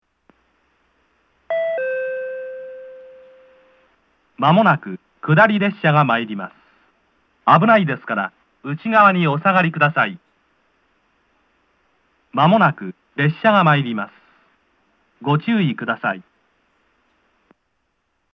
（男性）
接近放送
下り列車の接近放送です。こちらも同様。